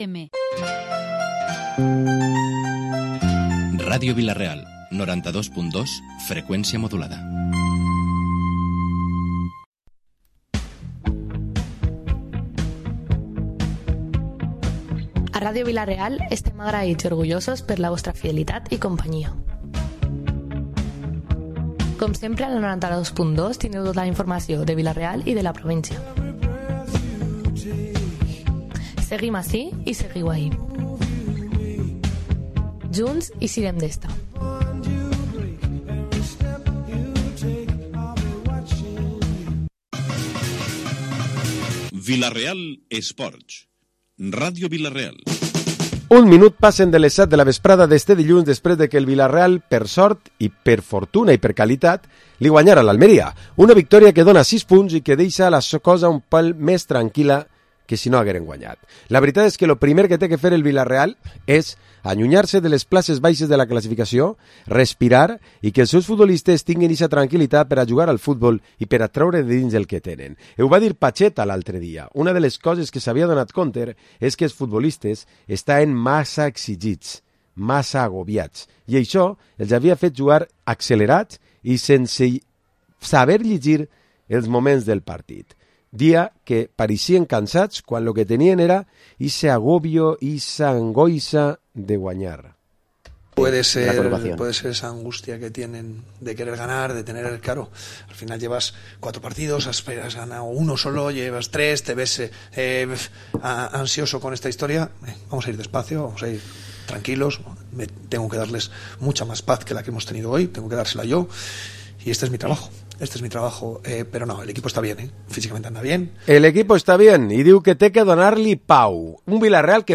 Programa esports tertúlia dilluns 18 de Setembre